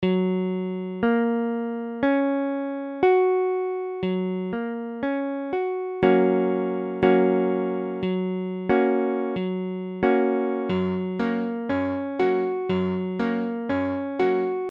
Gb : accord de Sol b�mol majeur Mesure : 4/4
Tempo : 1/4=60